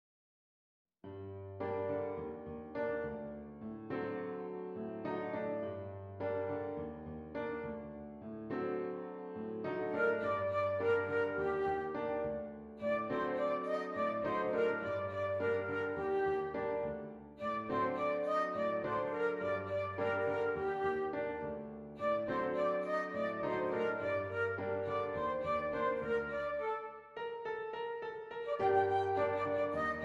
Flute Solo with Piano Accompaniment
G Minor
Moderate